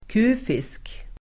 kufisk adjektiv, odd Uttal: [k'u:fisk] Böjningar: kufiskt, kufiska Synonymer: besynnerlig, egendomlig, excentrisk, konstig, märklig, skum, speciell, underlig Definition: som har underliga egenskaper Exempel: kufiska metoder (queer methods)